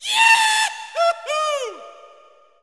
JAUCHZER   2.wav